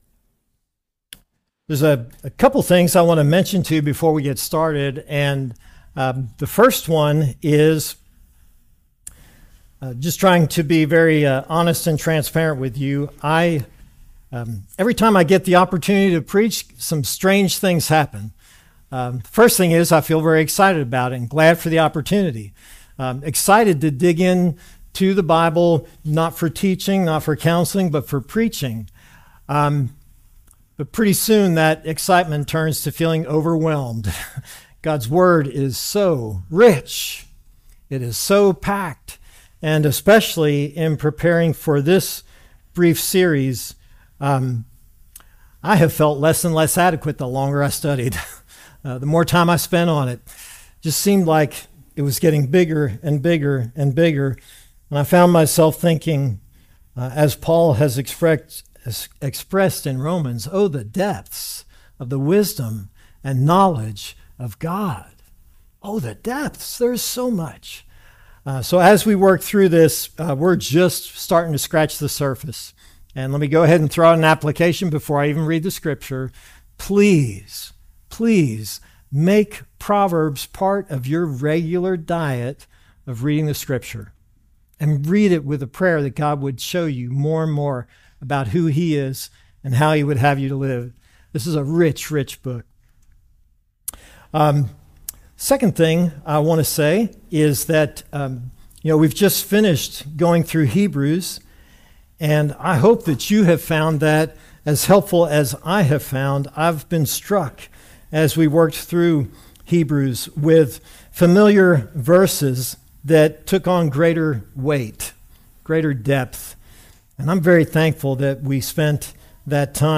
Service Type: Sermons